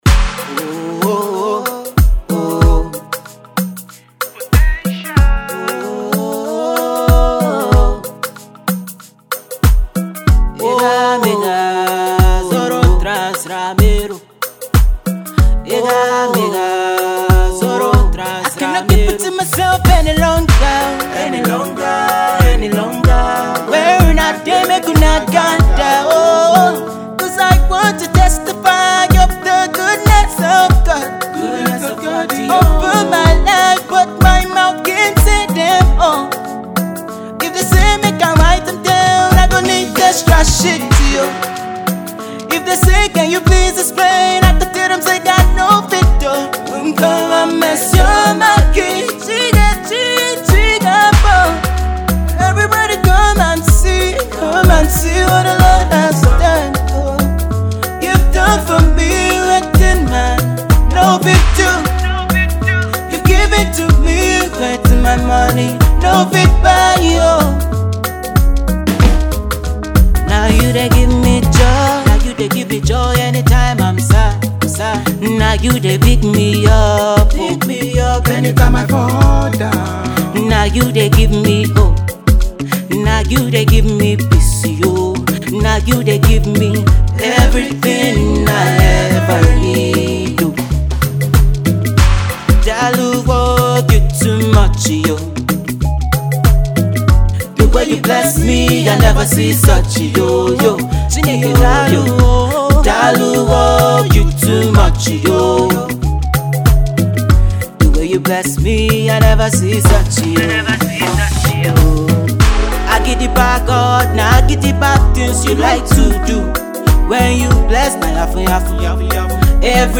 a fine blend of R&B and Afro beat.